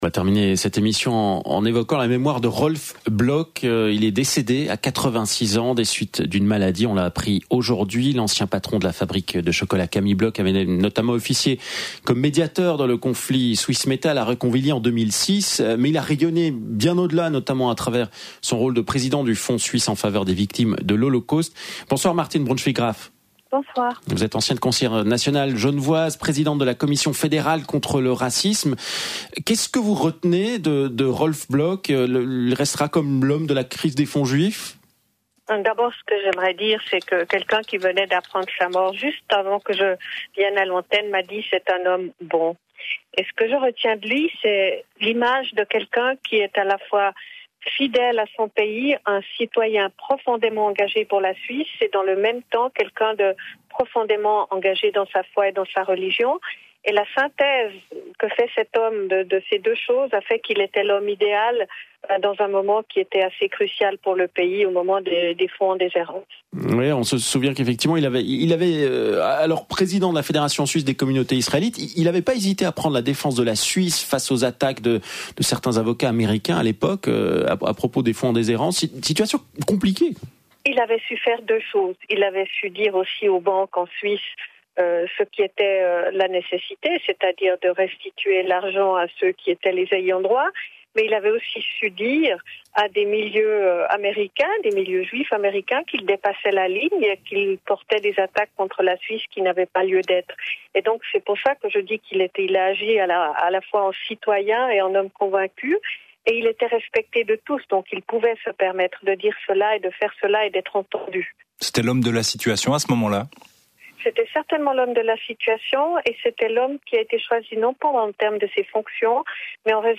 Martine Brunschwig Graf, présidente de la Commission fédérale contre le racisme, rend hommage à «un homme bon», qu’elle connaissait de très longue date.